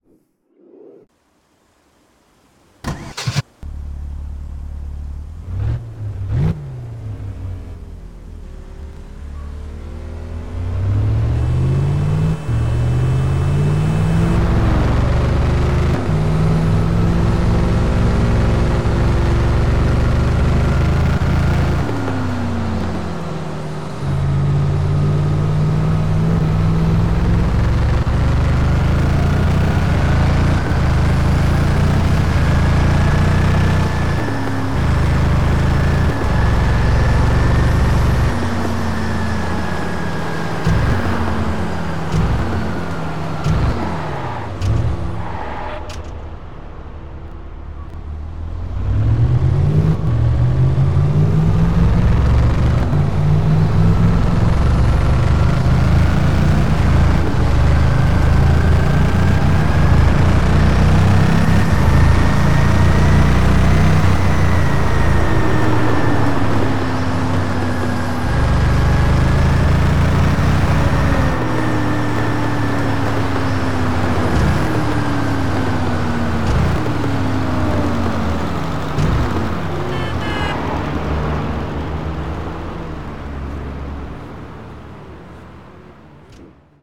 Mercedes-Benz